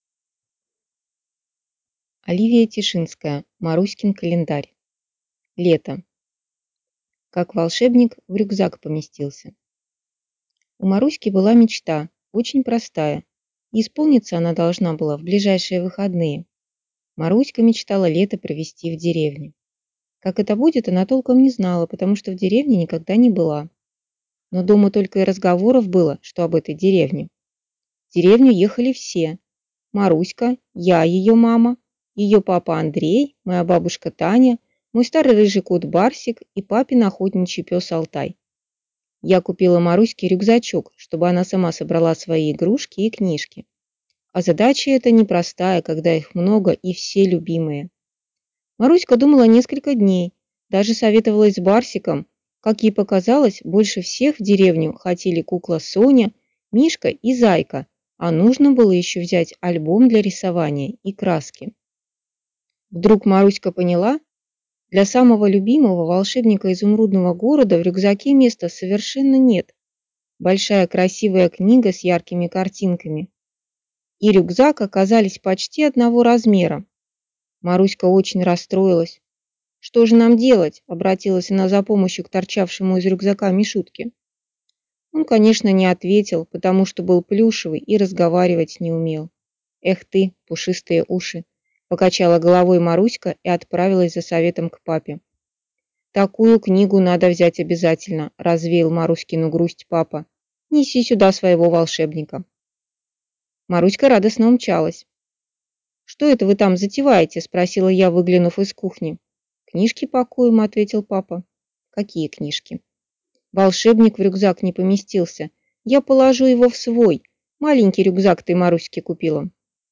Аудиокнига Маруськин календарь | Библиотека аудиокниг